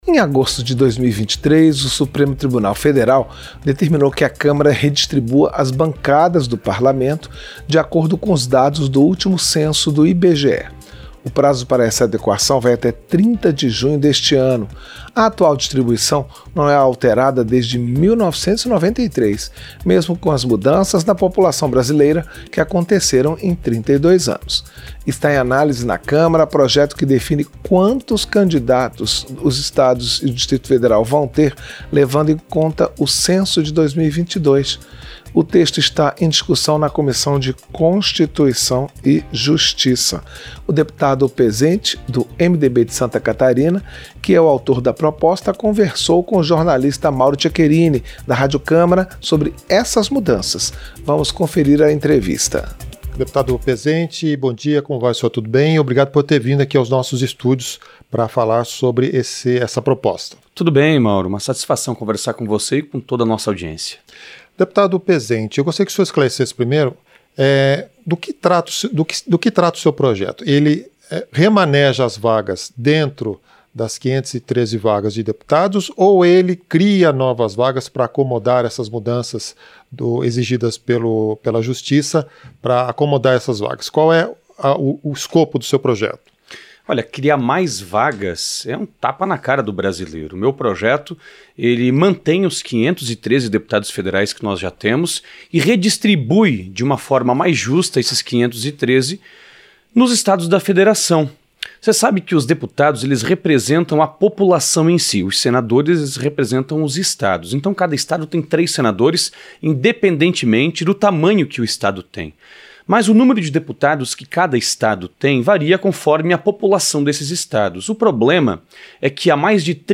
Entrevista - Dep. Pezenti (MDB-SC)